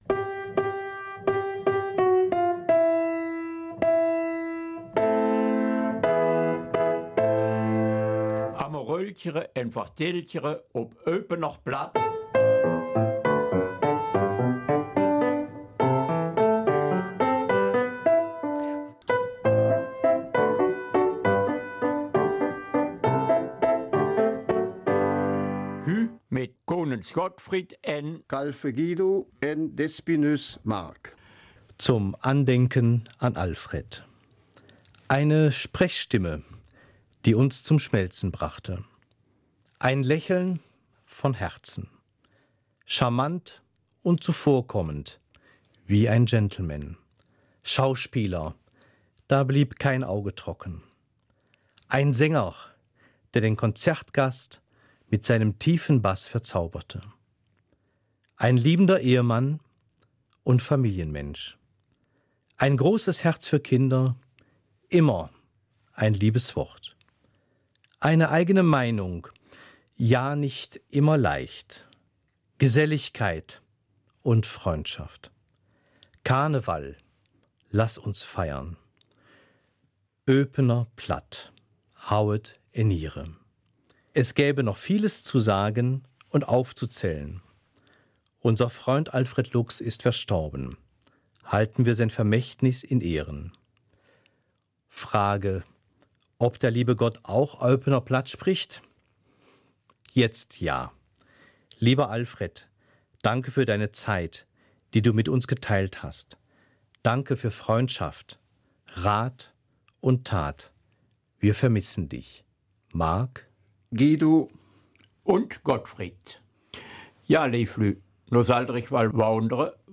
Eupener Mundart: Neues Moderatorenteam